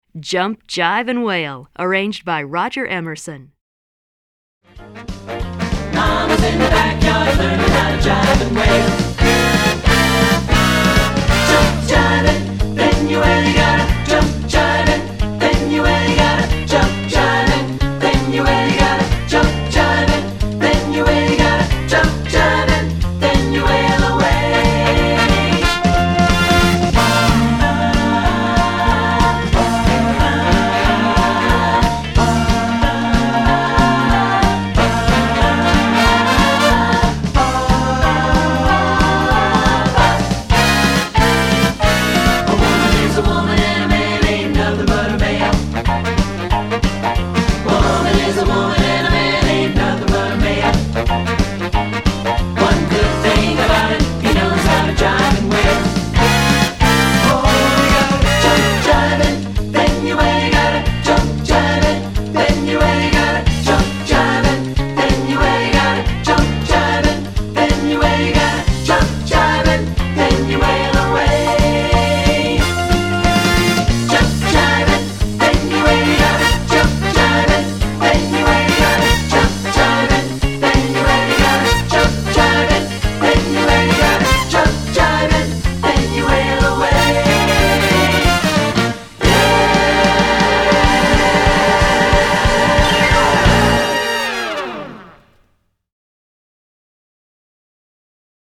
Chant Mixtes